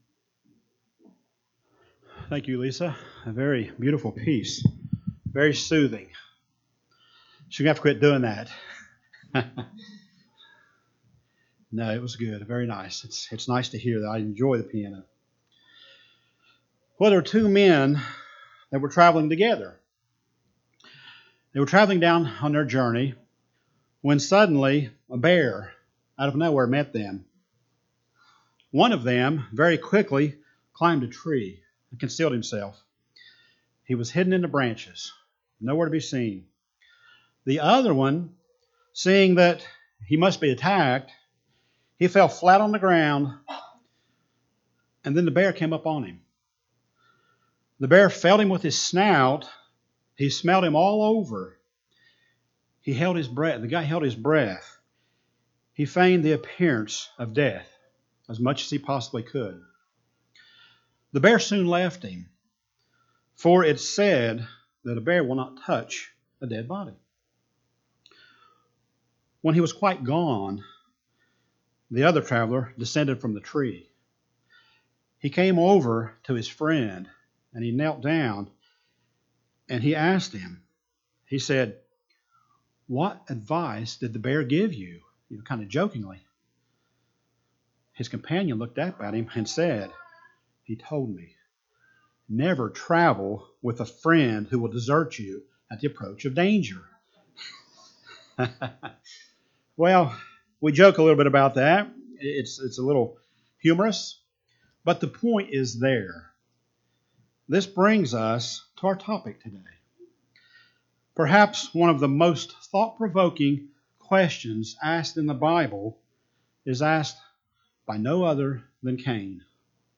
Today we will go through several points showing how to take care of others. After this sermon we should ask ourselves--"How well are we doing?".